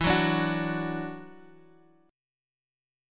E7sus2 chord